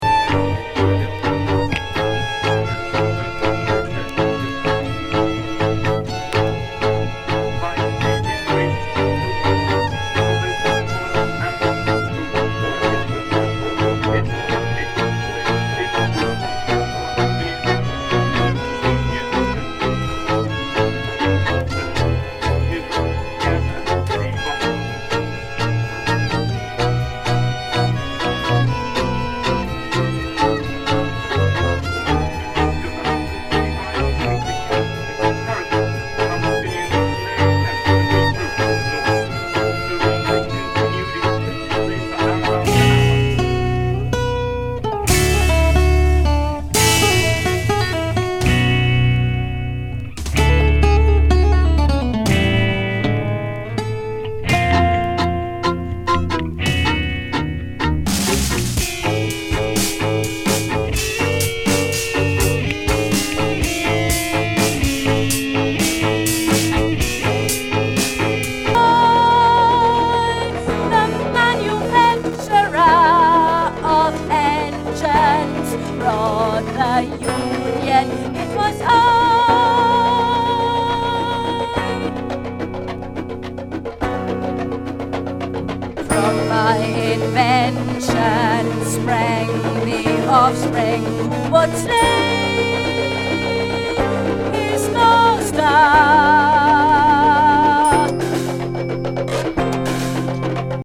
オリエンタル・プロッグ